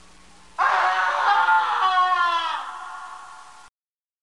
Scream Sound Effect
Download a high-quality scream sound effect.
scream-7.mp3